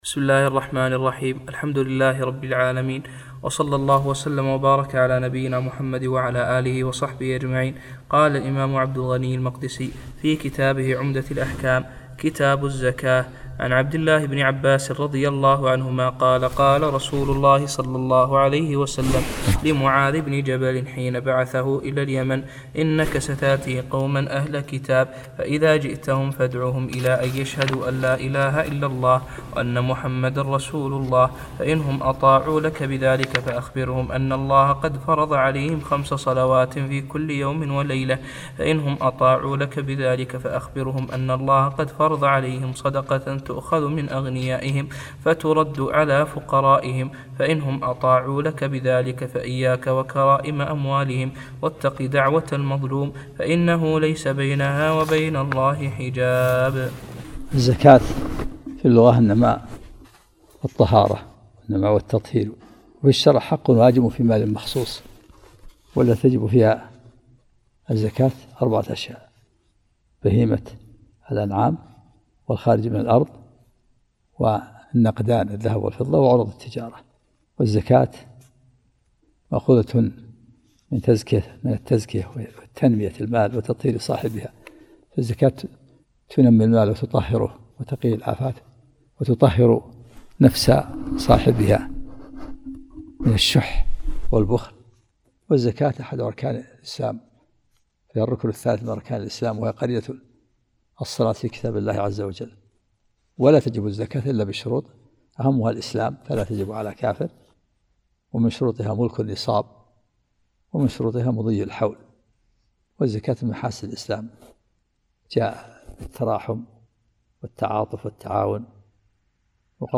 محاضرة صوتية مميزة، وفيها شرح الشيخ عبد العزيز بن عبد ا